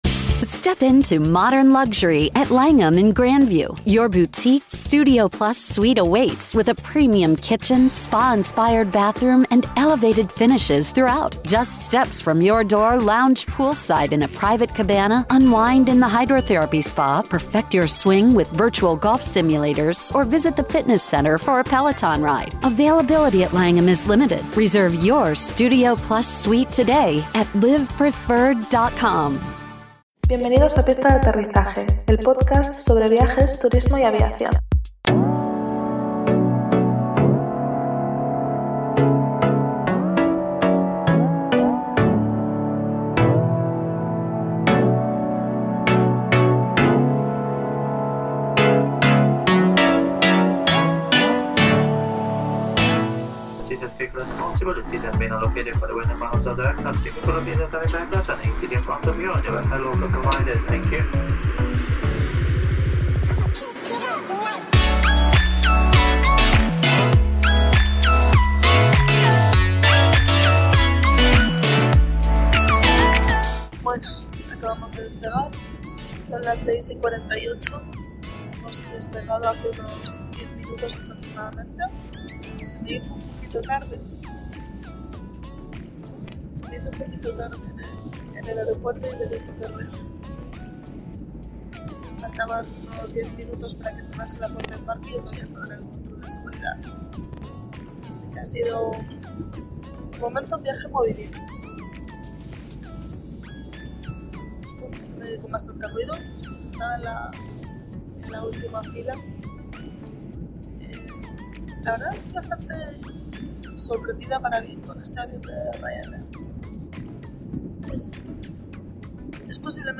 En este primer "podlog" (diario en formato podcast) os cuento brevemente como ha sido mi viaje y llegada a Lituania.